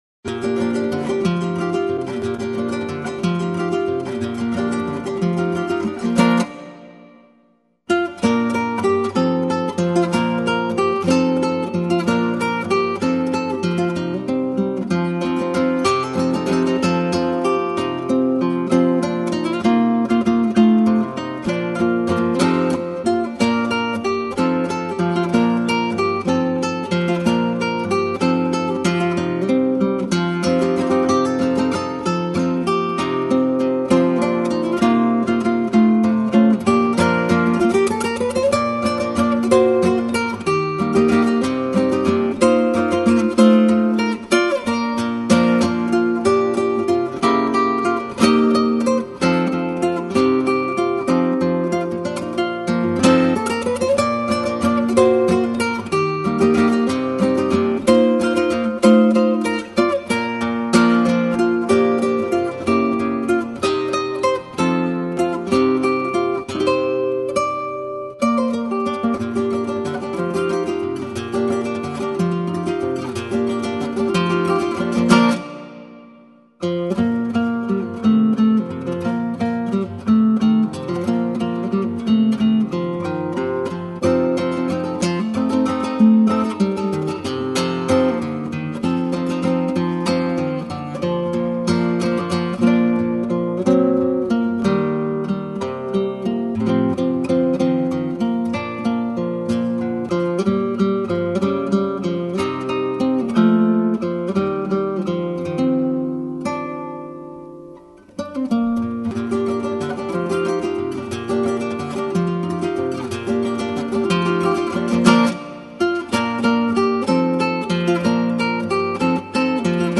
Portland Spanish Guitarist 1
Since he has chosen not to sing, he concentrated on his guitar skills.
This Portland Spanish Guitarist performs other types of music but have a genuine affinity towards the Flamenco and Spanish style.